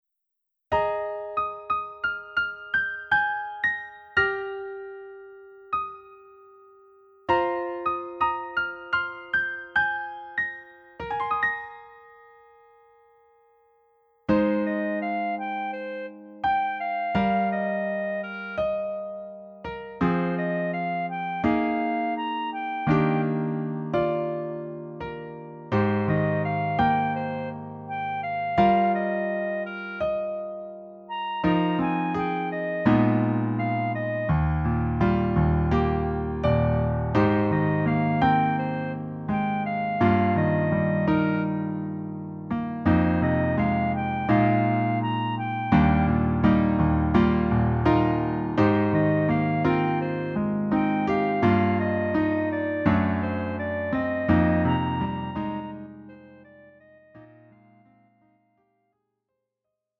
음정 -1키 4:59
장르 가요 구분 Lite MR
Lite MR은 저렴한 가격에 간단한 연습이나 취미용으로 활용할 수 있는 가벼운 반주입니다.